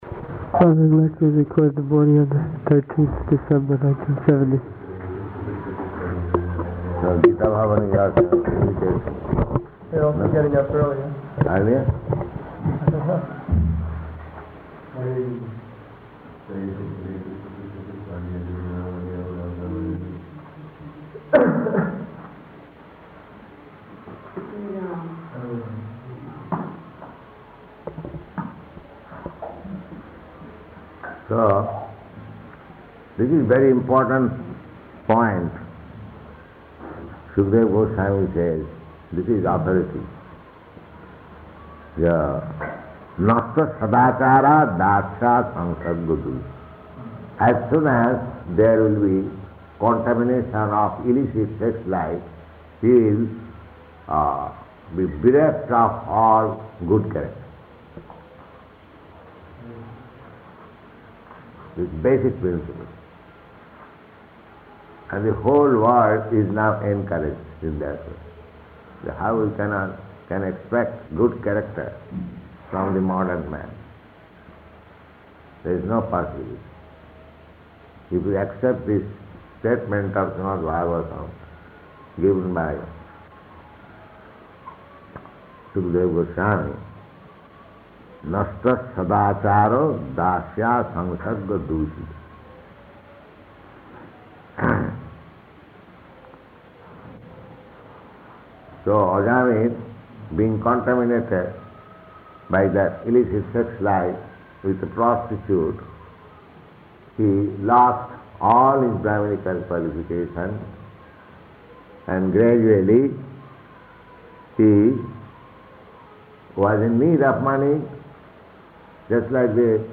Location: Indore